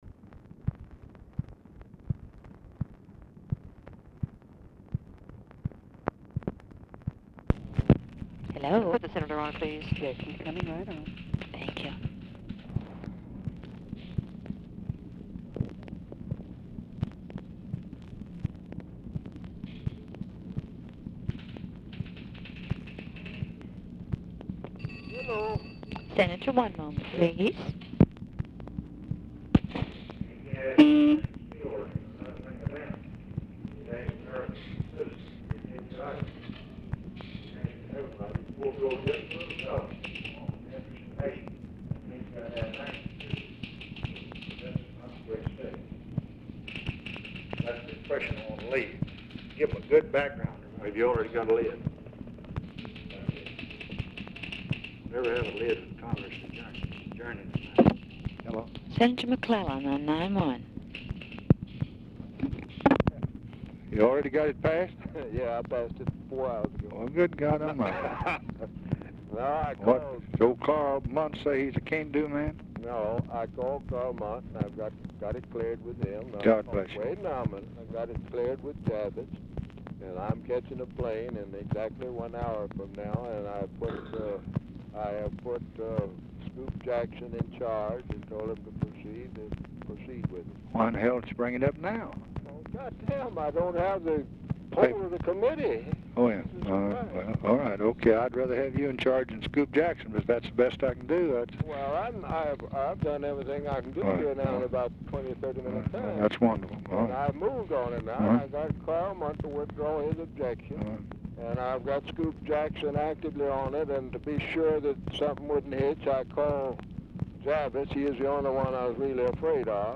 Telephone conversation # 9034, sound recording, LBJ and JOHN MCCLELLAN, 10/22/1965, 5:10PM | Discover LBJ
MCCLELLAN ON HOLD 1:02; OFFICE CONVERSATION ABOUT PRESS LID PRECEDES CALL
Format Dictation belt
Location Of Speaker 1 Oval Office or unknown location